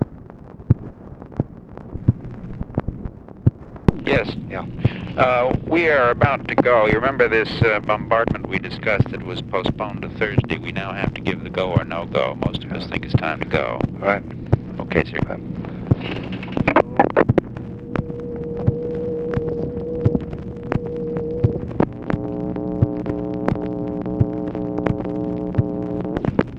Conversation with MCGEORGE BUNDY, October 20, 1964
Secret White House Tapes